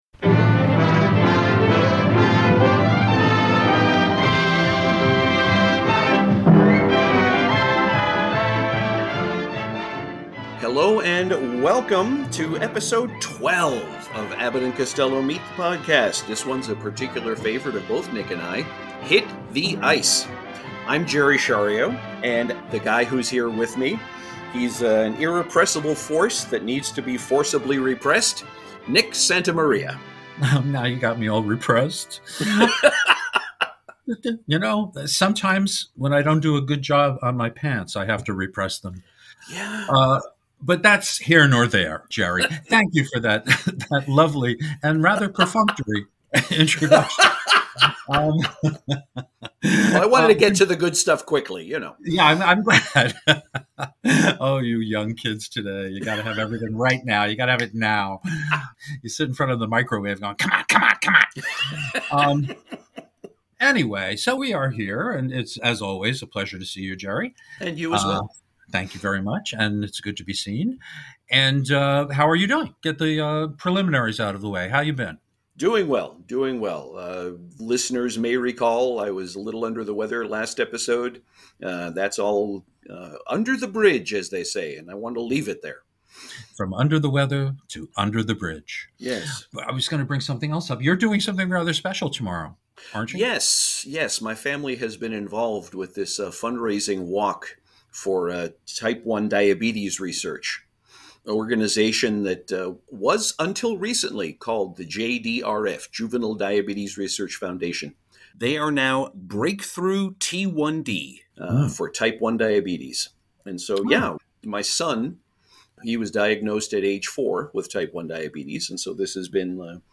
It's the boys vs. Sheldon Leonard and his mob. You'll also hear a very important sound bite from Bud Abbott's career.